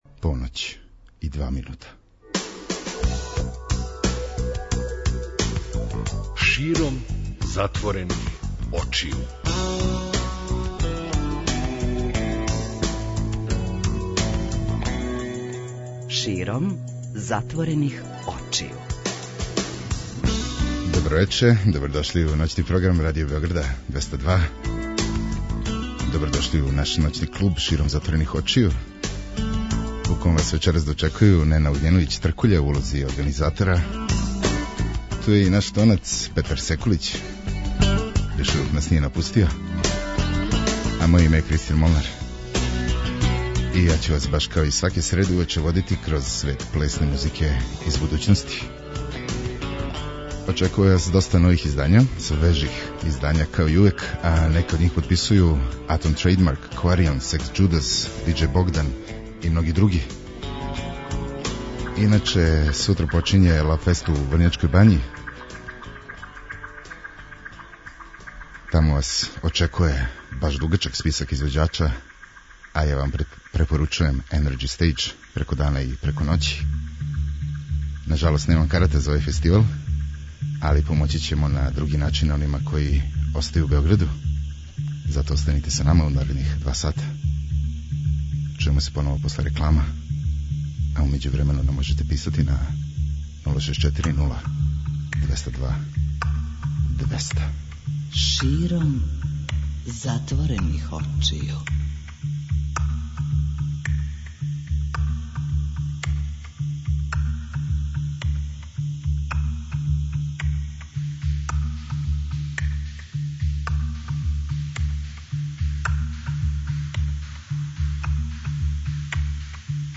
Ди-џеј